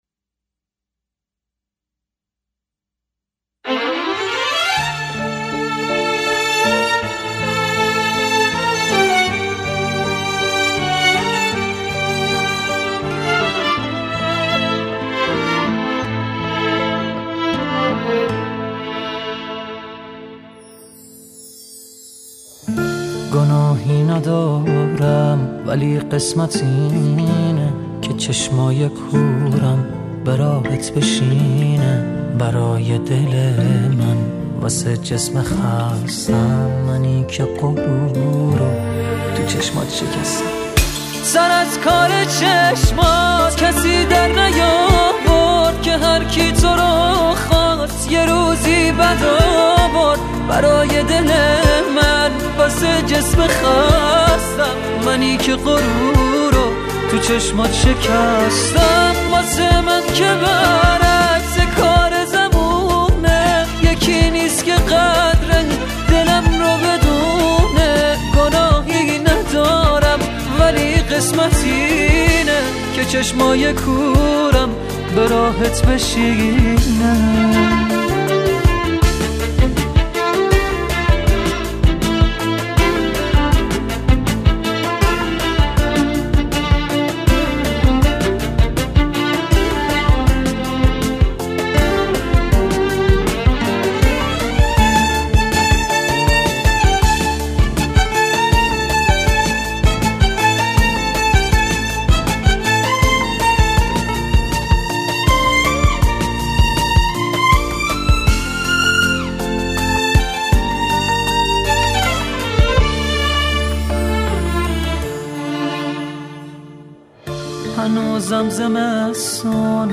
ژانر: پاپ